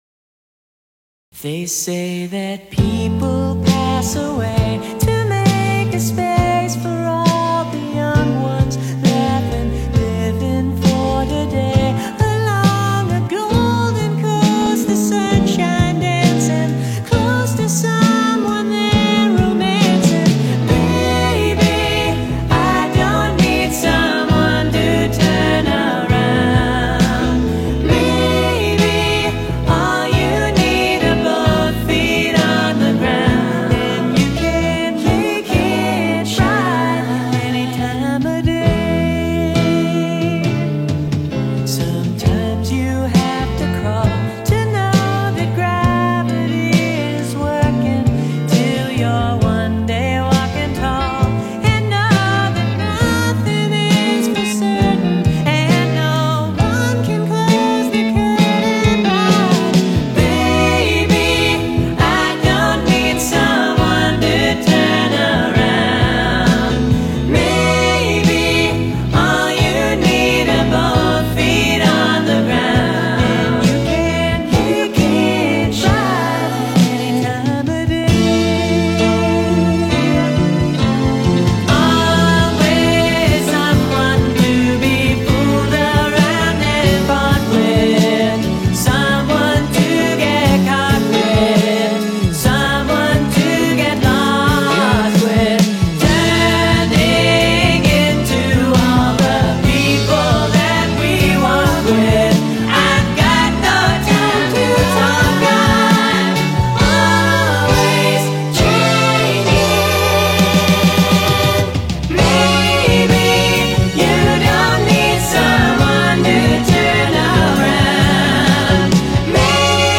American rock band